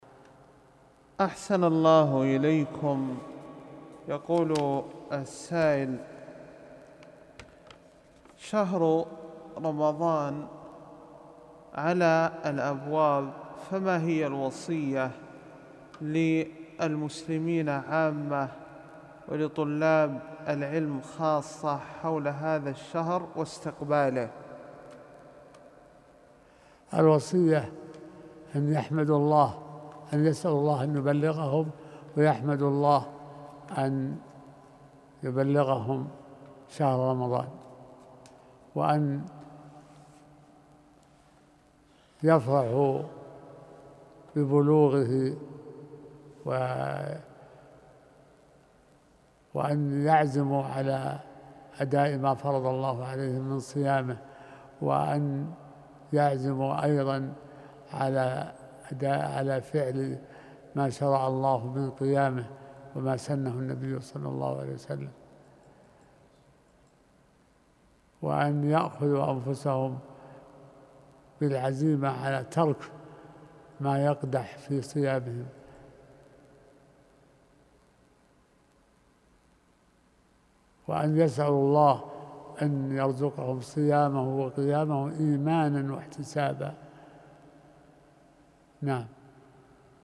كلمة في استقبال شهر رمضان